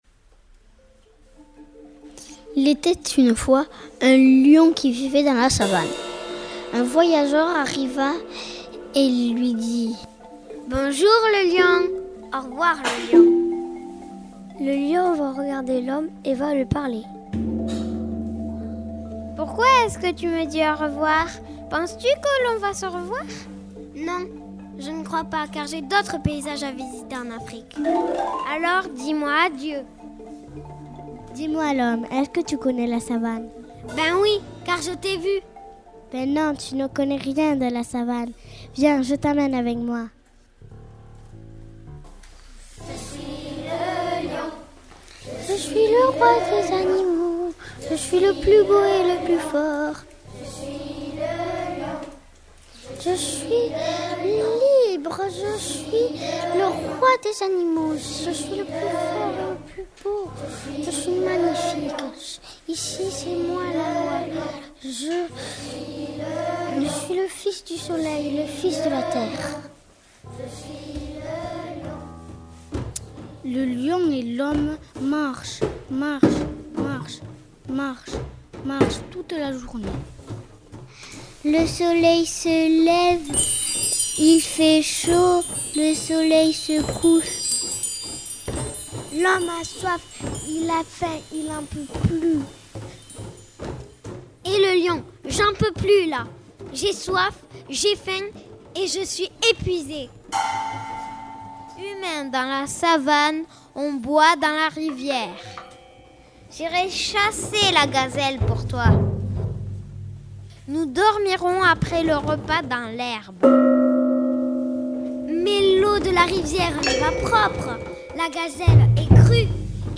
Les enfants du camp musique du 7 au 9 juillet ont créé et enregistré une oeuvre que vous pouvez écouter en ligne.